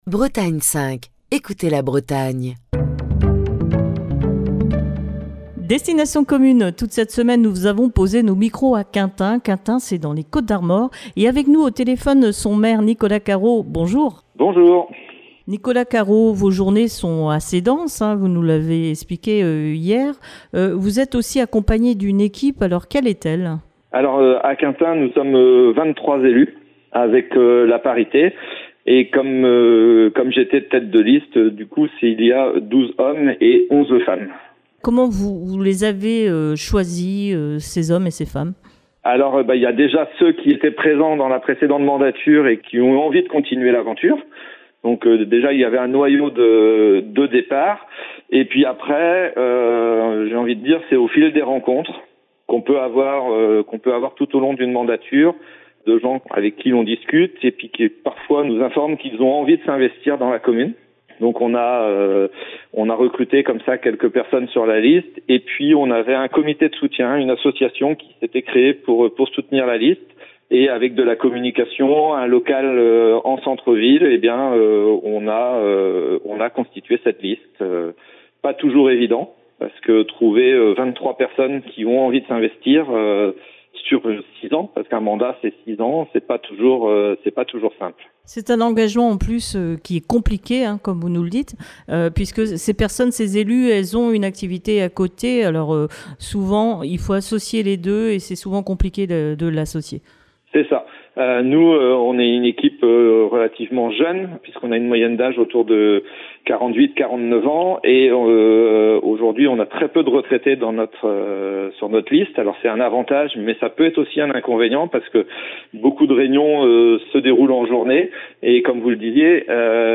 est au téléphone avec le maire de Quintin, Nicolas Carro, qui vous propose de partir à la découverte de sa ville et de partager son quotidien d'élu.